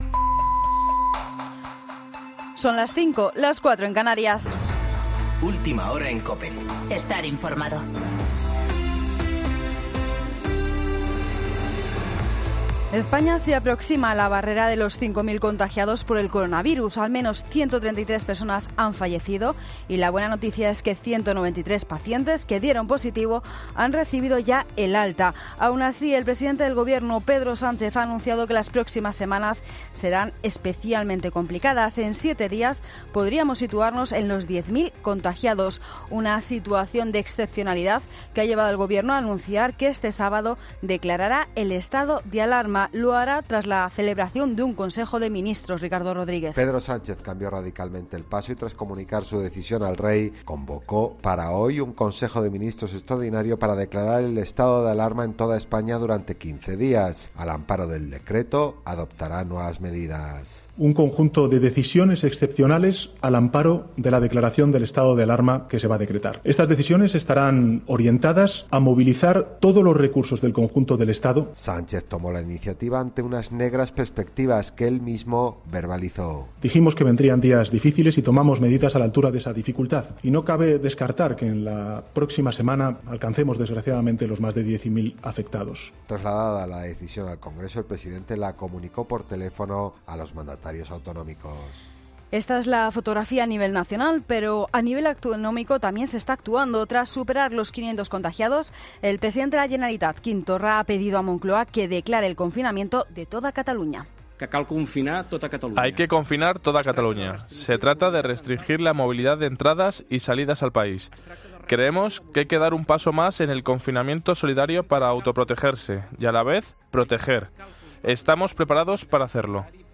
Boletín de noticias COPE del 14 de marzo de 2020 a las 05.00 horas